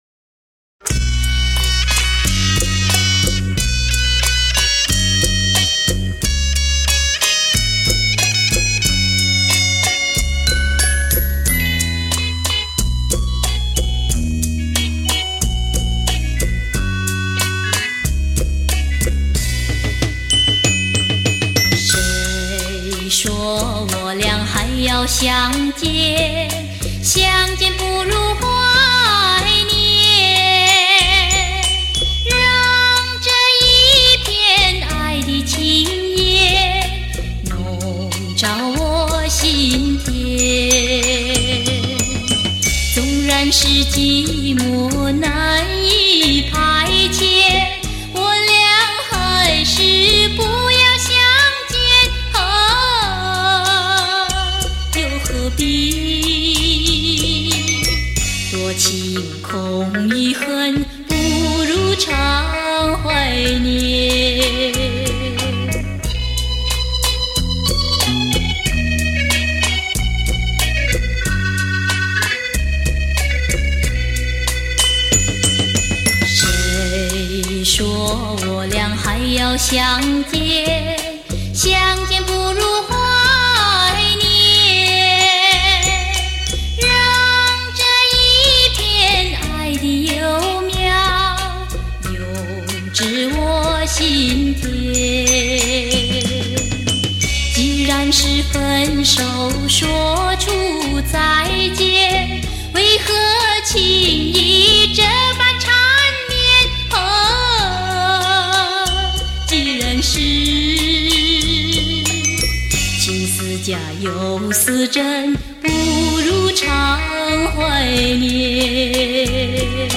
乐队伴奏